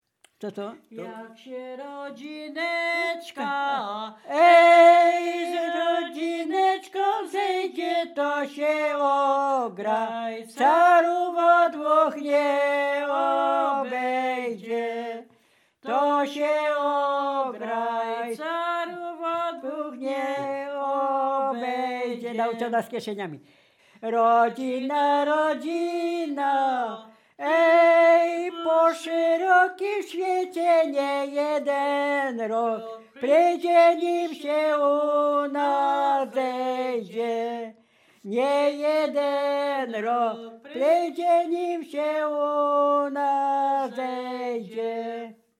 liryczne żartobliwe